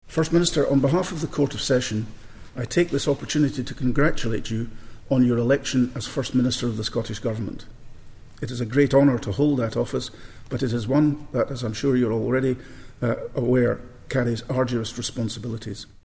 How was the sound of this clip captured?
The SNP leader appeared before Scotland’s most senior judge at the Court of Session in Edinburgh – pledging to devote himself to the job: